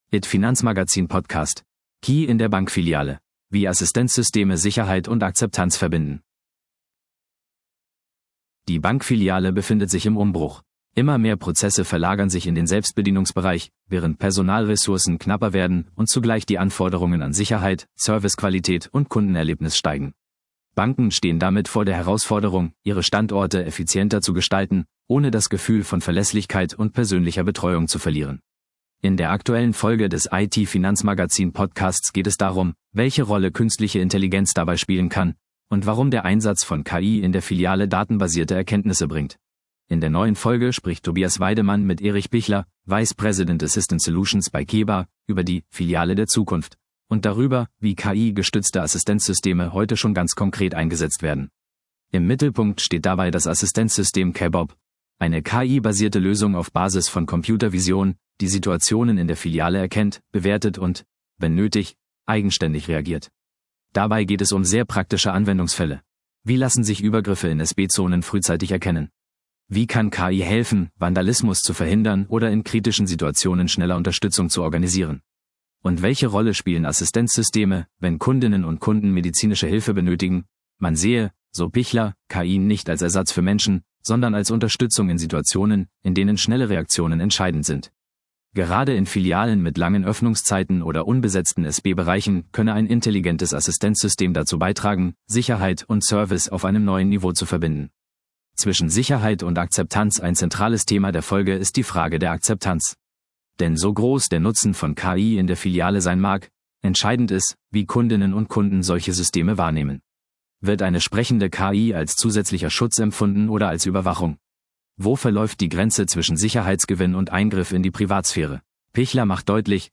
Neben den strategischen Fragen werfen die Gesprächspartner auch einen Blick auf die technologische Architektur hinter KeBob.